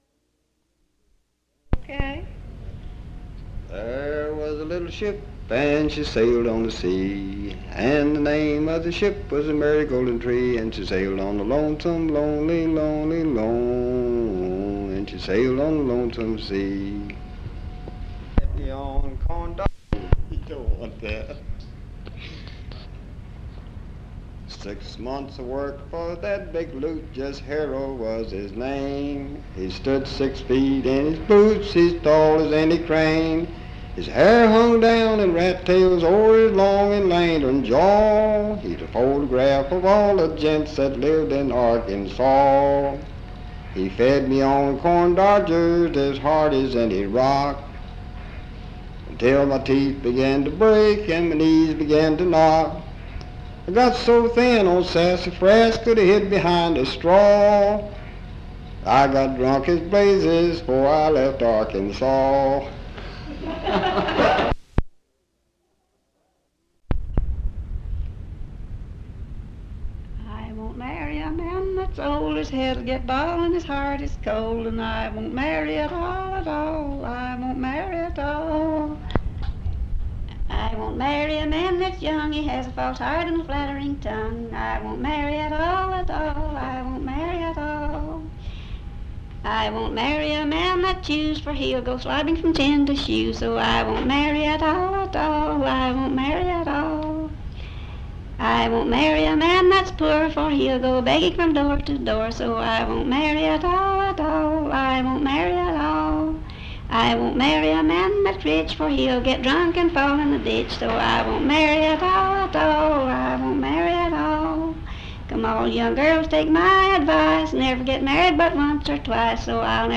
Recording Session
Folksong